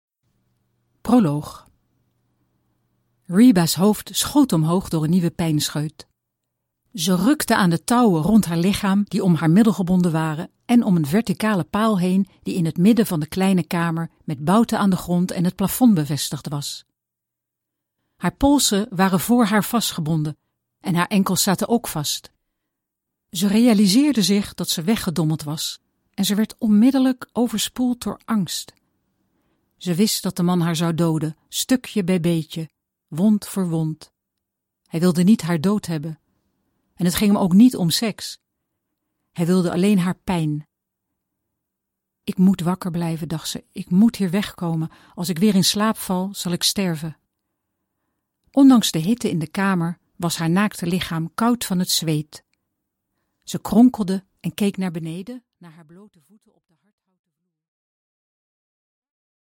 Аудиокнига Eens Weg | Библиотека аудиокниг